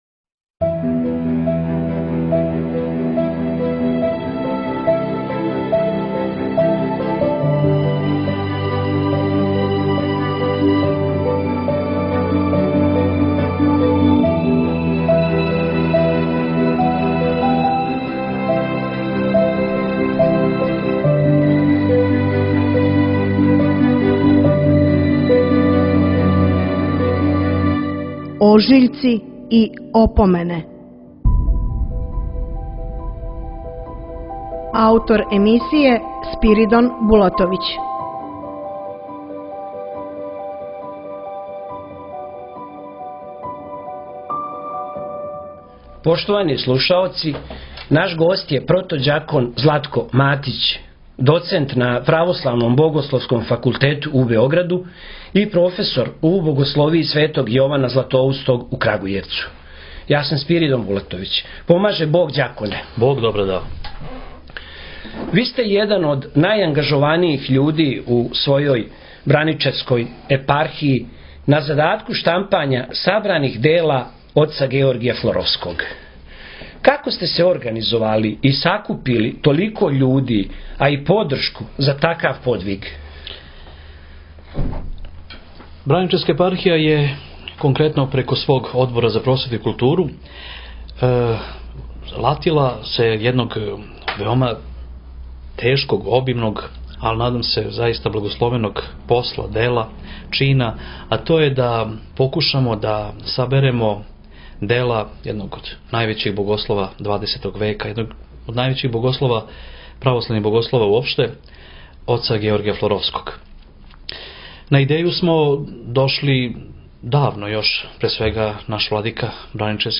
9.3.2019. | Култура » Радио гостовања